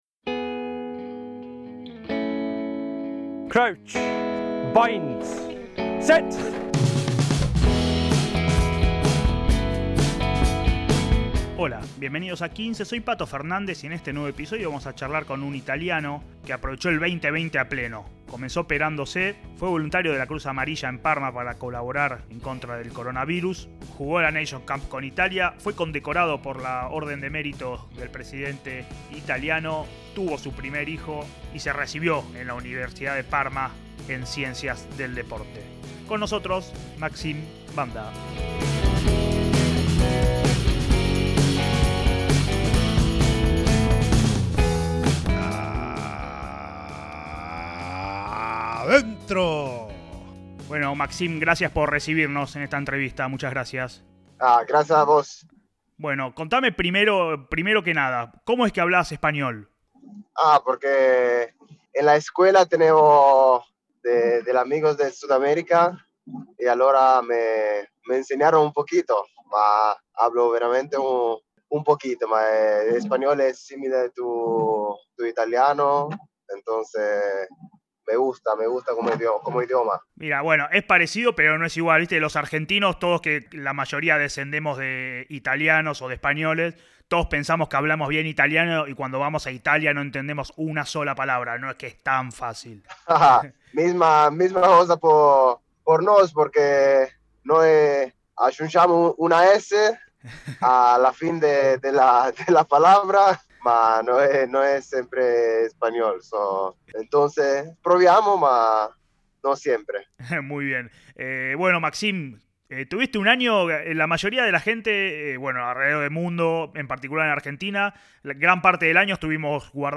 ¡Charlas de rugby con los protagonistas!